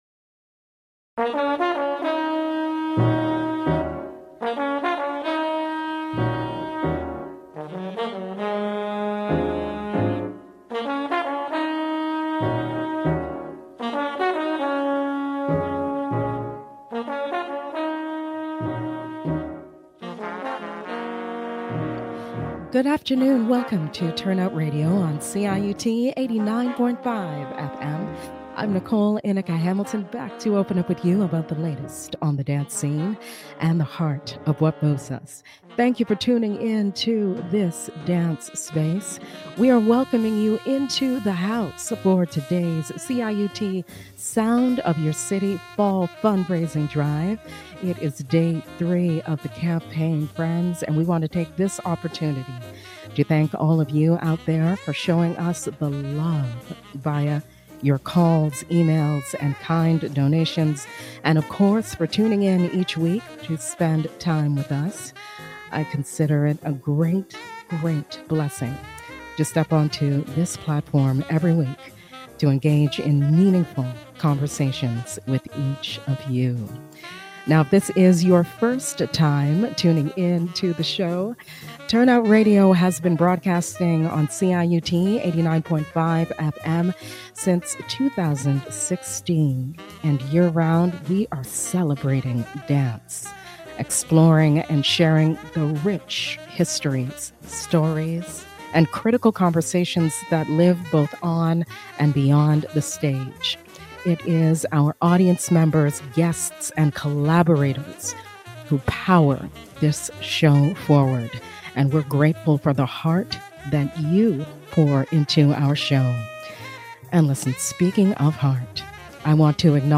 **This interview is a featured segment of the 2024 CIUT 89.5 FM Fall Fundraising Drive.